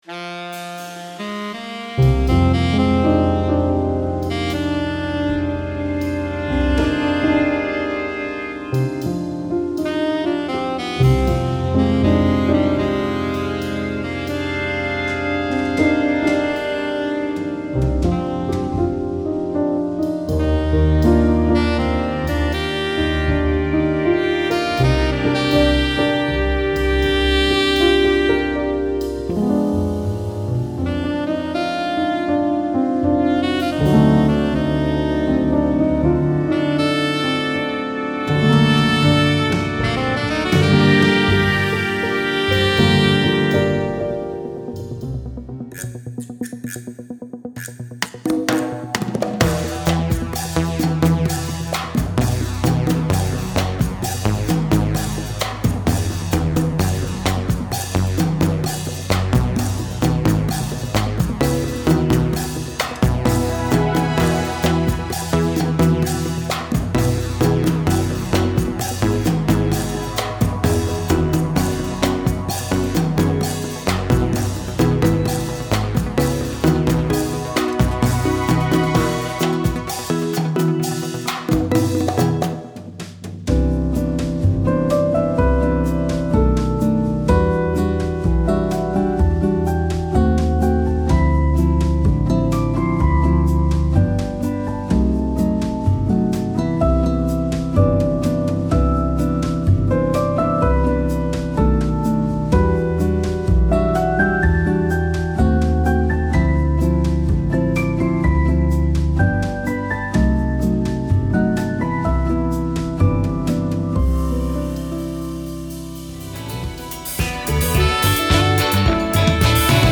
Contemporary Styles Medley (Jazz, Cha Cha, Bossa Nova, Disco, Baiao, Hip-Hop)